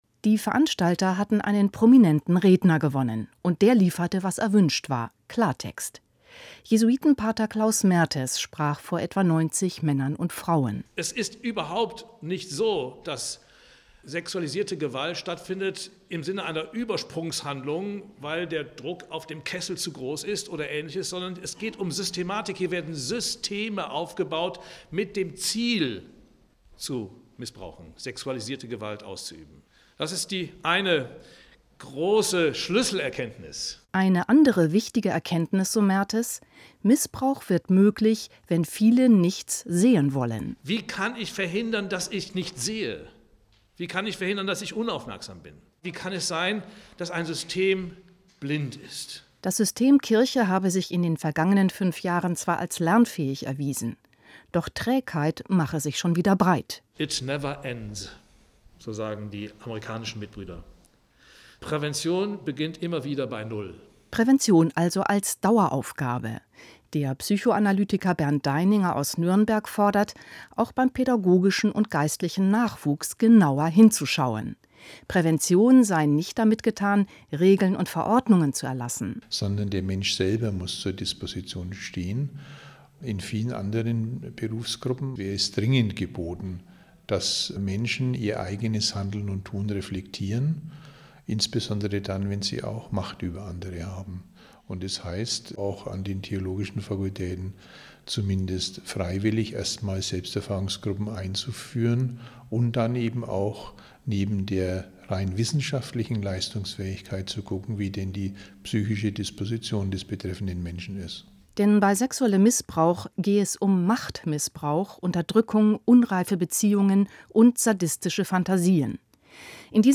Hörfunkbeitrag